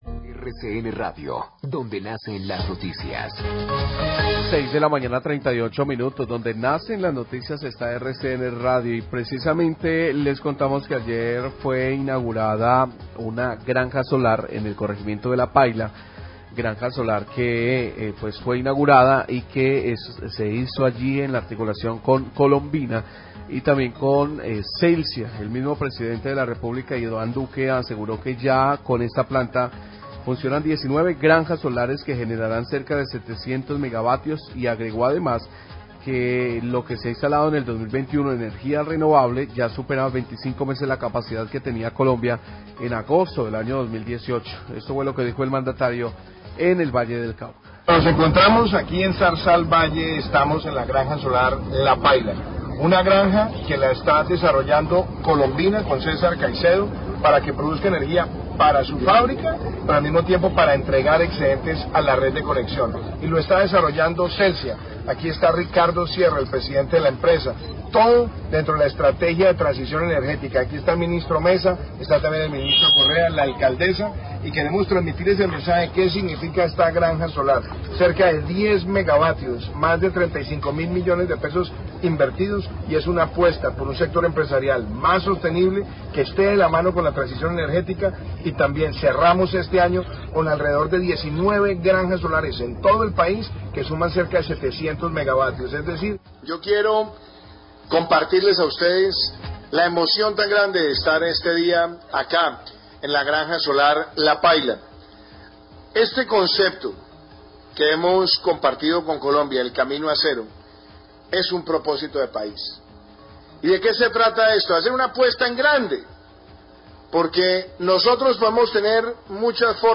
Radio
Apartes de los discursos del presidente Iván Duque y del Ministro de Minas y Energía, Diego Meza, durante el acto de inauguración de la granja solar de Celsia en La Paila y la cual fue desarrollada en conjunto con la empresa Colombina.